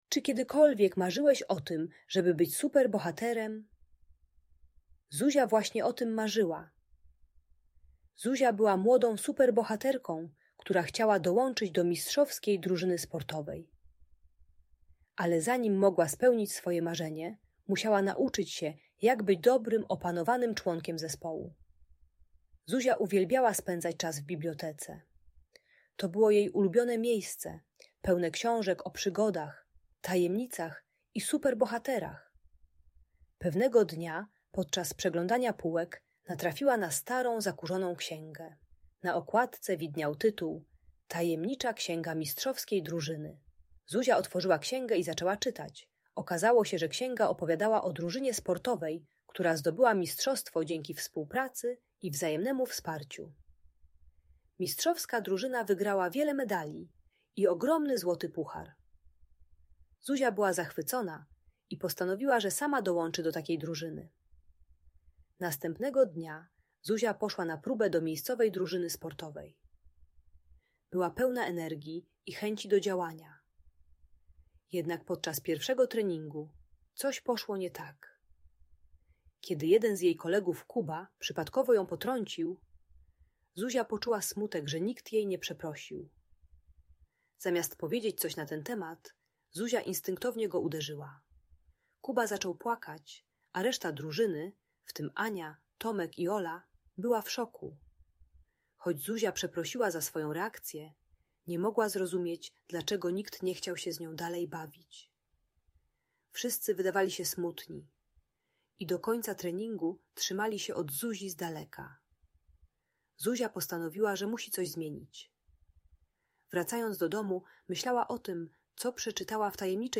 Opowieść o Zuzi - młodej super bohaterce - Audiobajka dla dzieci